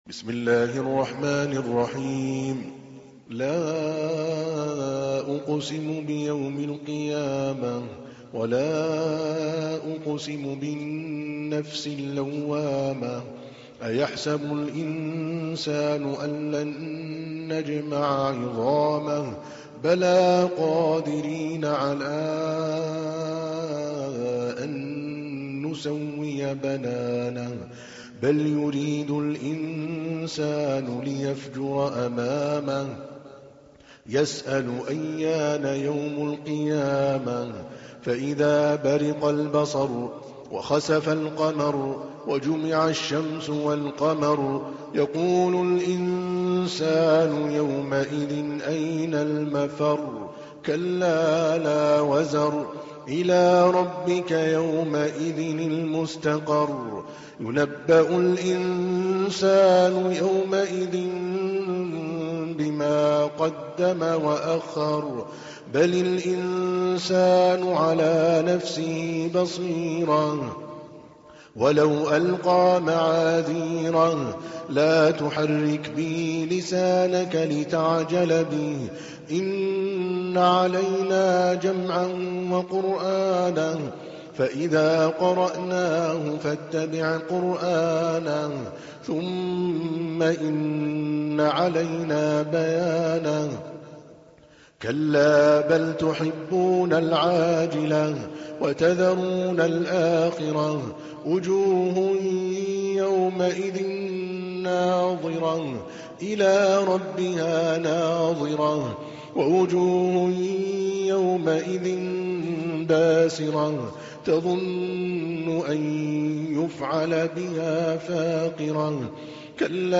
تحميل : 75. سورة القيامة / القارئ عادل الكلباني / القرآن الكريم / موقع يا حسين